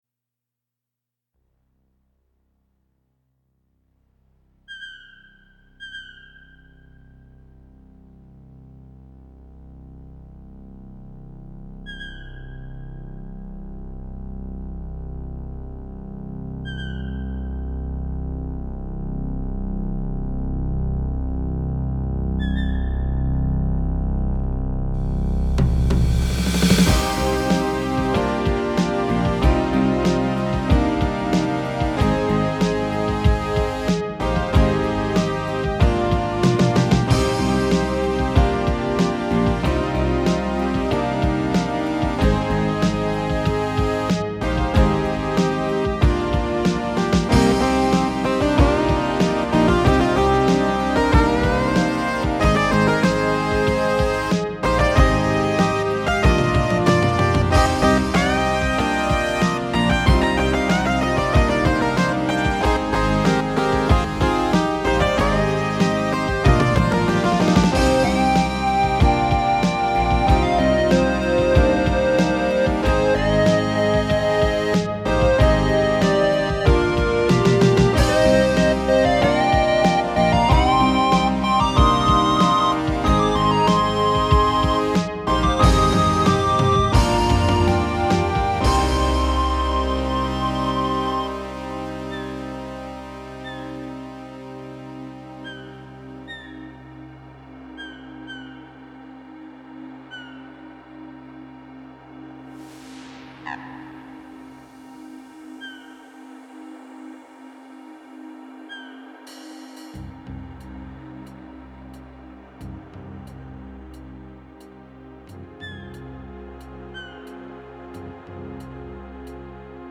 As far as I am aware it is an MT-32 composition.
Here is a recording of the song from my RWI-Modded MT-32.